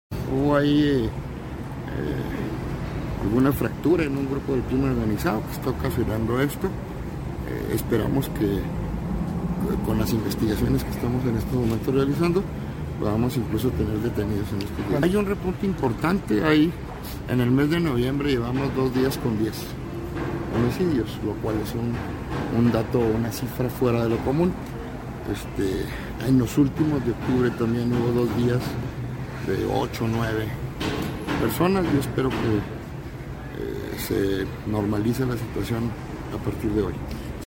AUDIO: CÉSAR JAÚREGUI, FISCAL GENERAL DEL ESTADO (FGE)